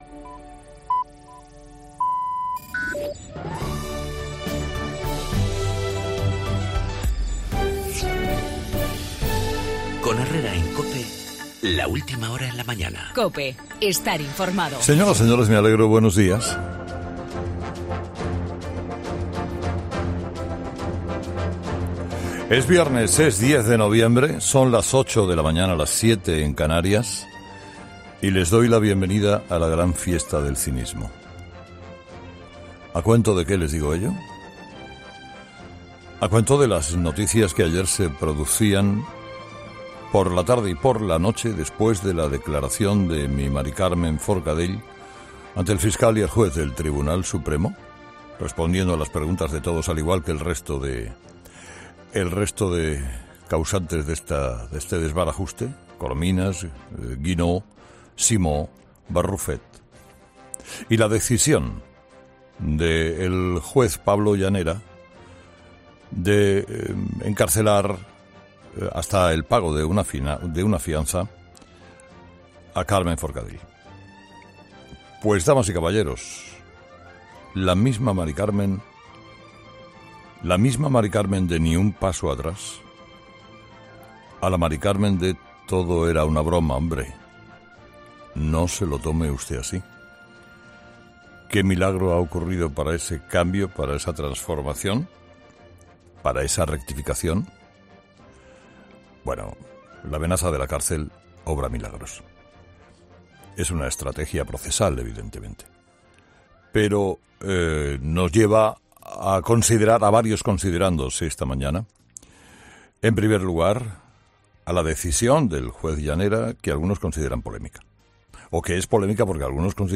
AUDIO: La presidenta del Parlamento catalán y el resto de la Mesa optan por la Constitución para evitar la cárcel, en el editorial de Carlos Herrera
Monólogo de las 8 de Herrera